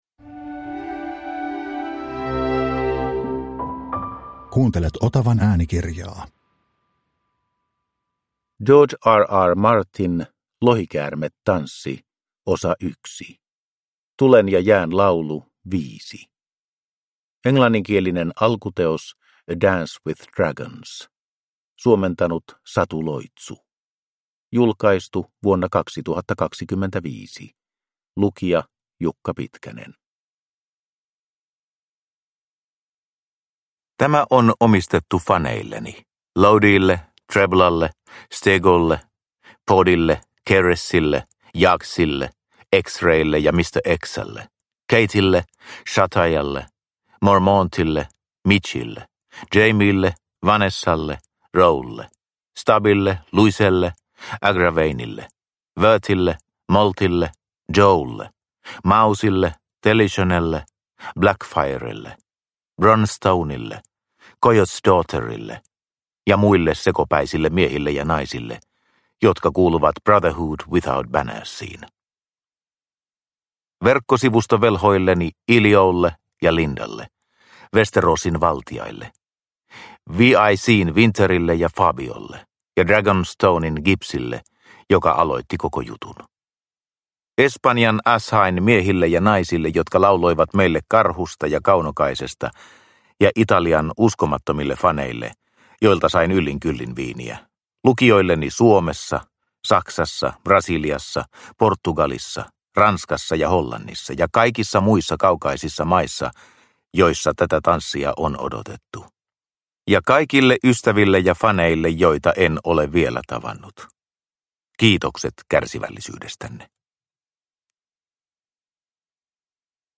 Lohikäärmetanssi 1 (ljudbok) av George R. R. Martin